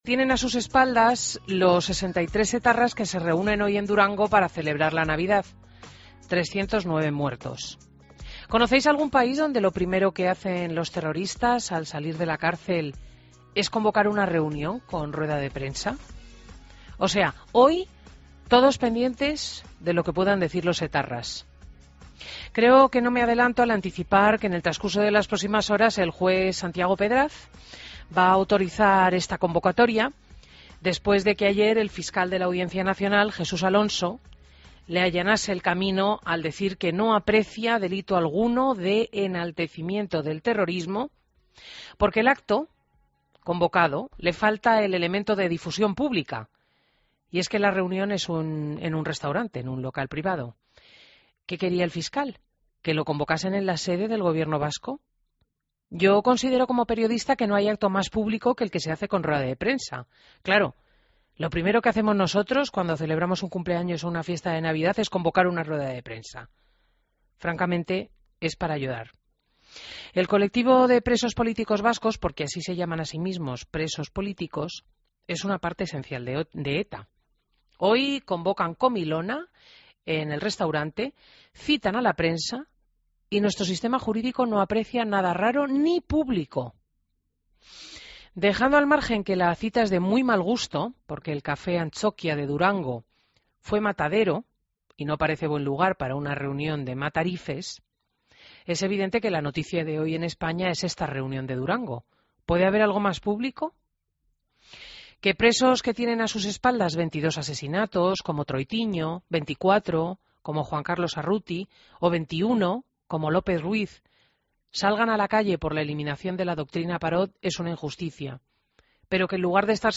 AUDIO: Entrevista a Ángeles Pedraza, presidenta de la AVT